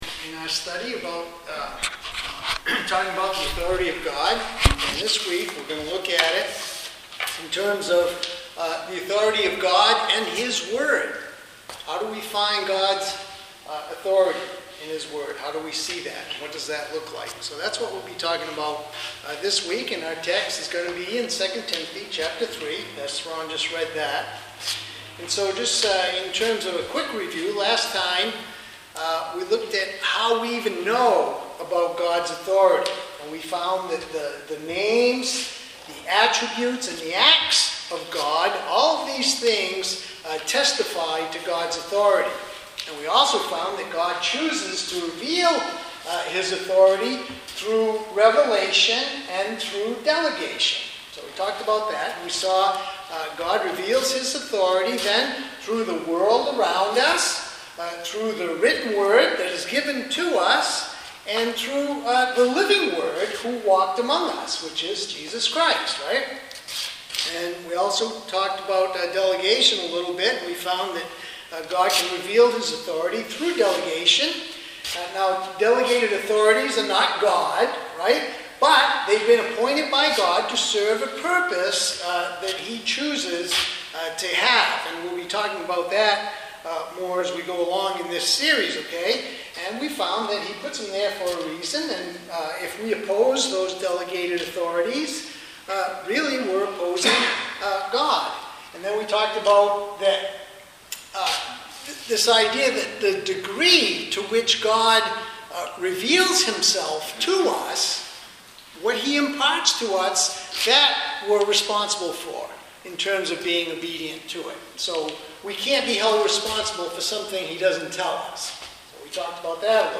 Sermons: Former Speakers